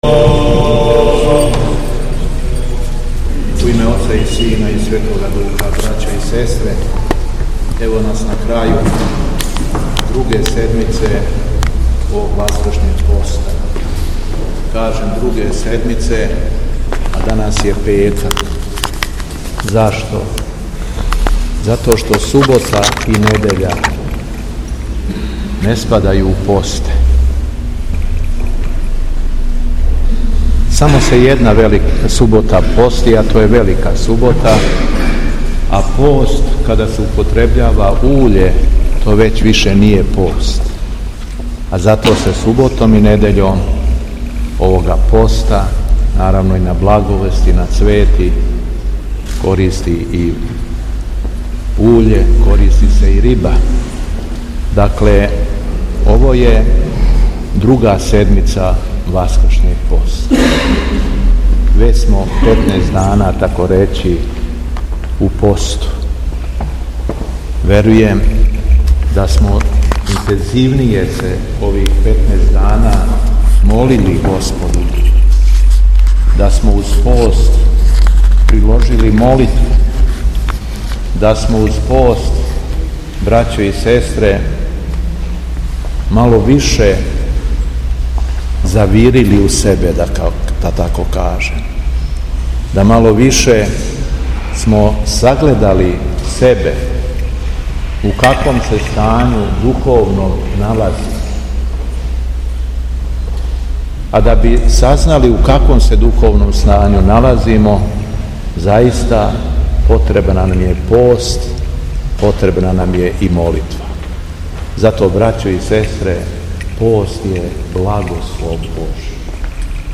У петак друге недеље Часног поста, 14. марта 2025. године, Његово Високопреосвештенство Архиепископ крагујевачки и Митрополит шумадијски Господин Јован служио је Литургију Пређеосвећених Дарова у храму Преображења Господњег у Смедеревској Паланци, архијерејско намесништво јасеничко.
Беседа Његовог Високопреосвештенства Митрополита шумадијског г. Јована
Високопреосвећени се обратио свештвенству и верном народу пригодном беседом: